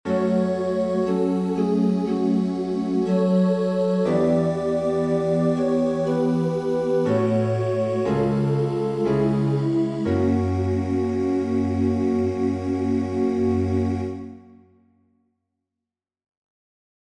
Chants de Prière universelle Téléchargé par